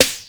Snare (3).wav